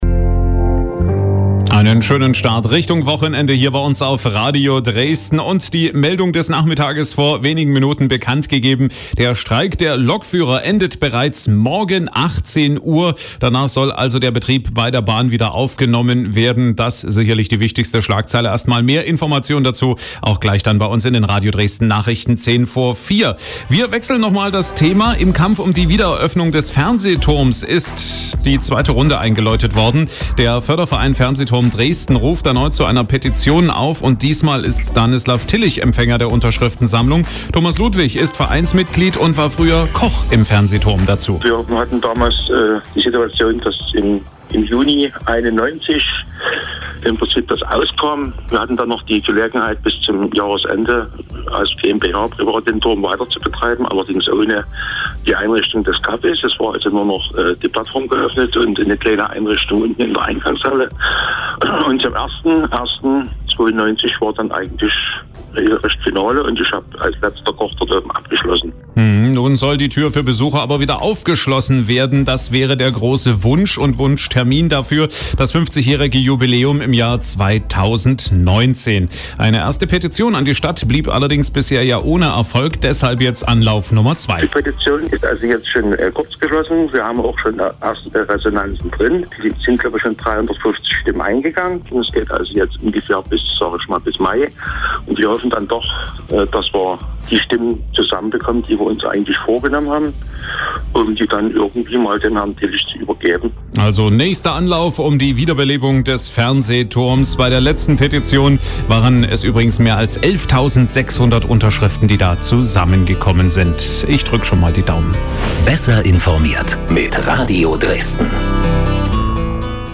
Radio Dresden im Interview